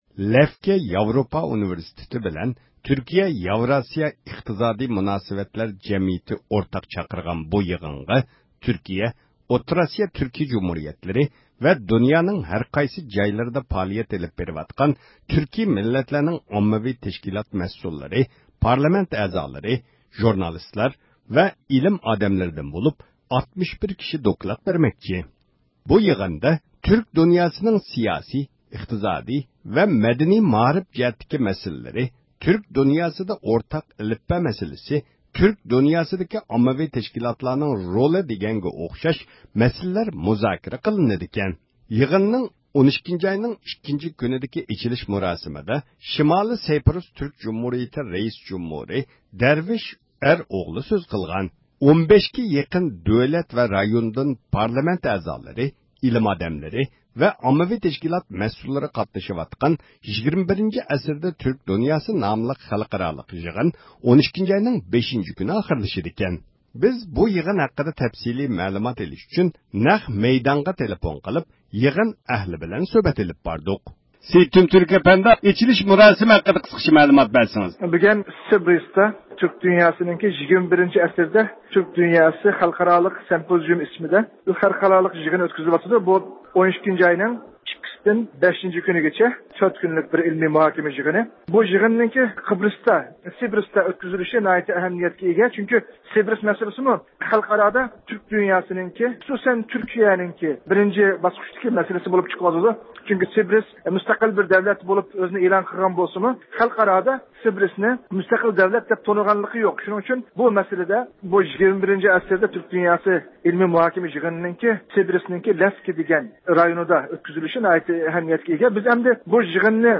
بىز بۇ يىغىن ھەققىدە تەپسىلىي مەلۇمات ئېلىش ئۈچۈن نەق مەيدانغا تېلېفون قىلىپ يىغىن ئەھلى بىلەن سۆھبەت ئېلىپ باردۇق.